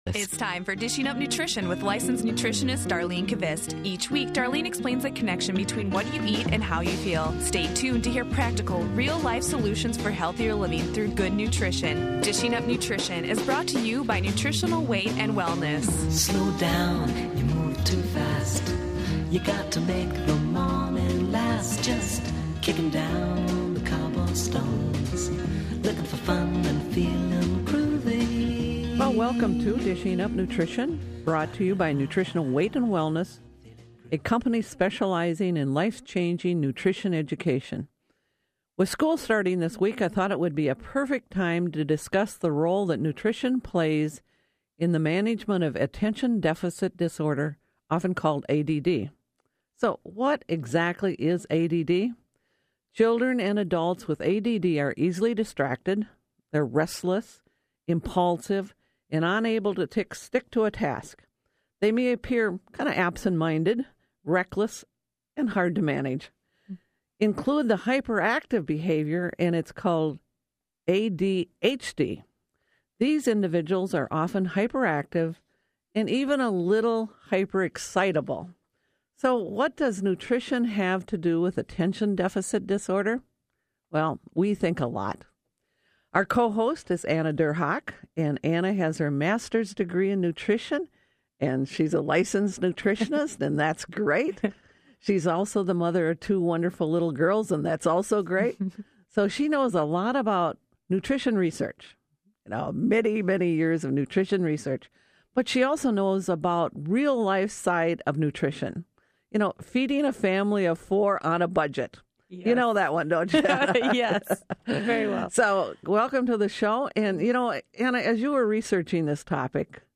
Nutrition for ADD/ADHD | Interview